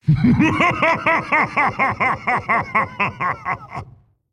lordjaraxxus-attack-laugh.mp3